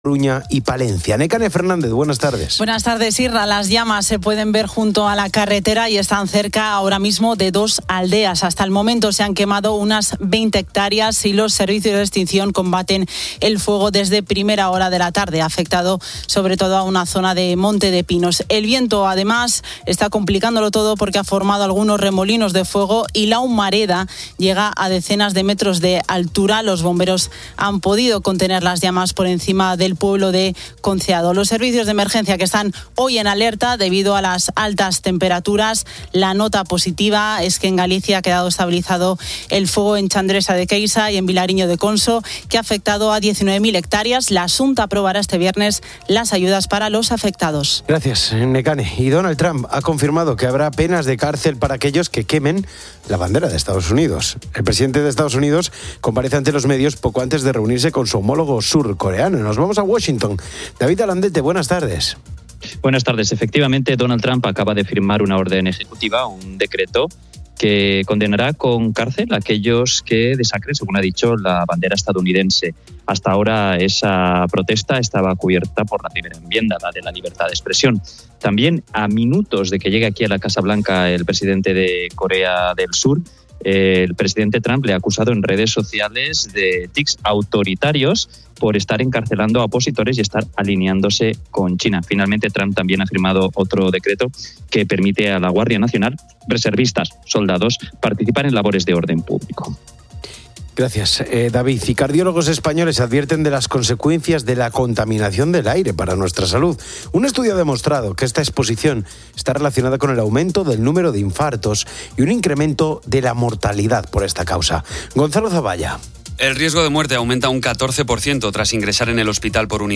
Finalmente, se informa sobre supuestos casos de corrupción en Argentina relacionados con Javier Milei y su hermana, y una entrevista con un detective privado que desmiente mitos sobre su profesión y describe los tipos de casos que manejan.